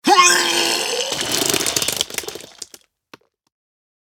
Download Vomit sound effect for free.
Vomit